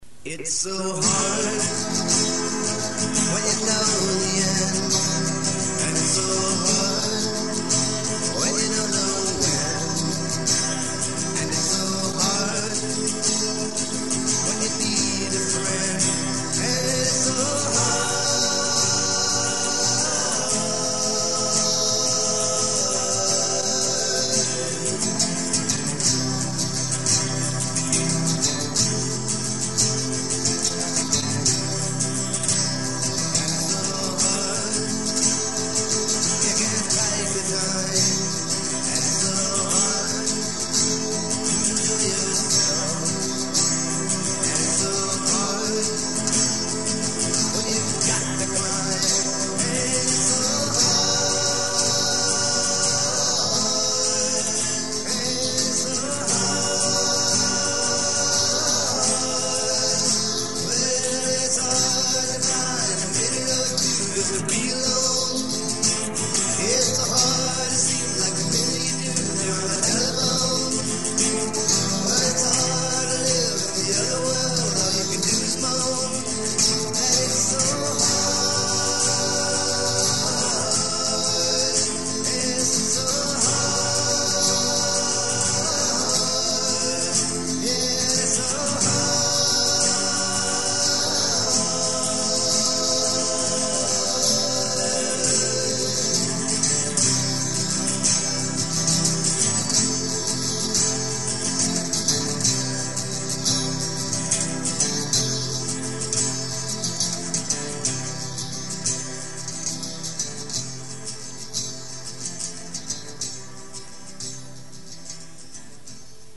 I had inadvertently fingered an Fmaj7.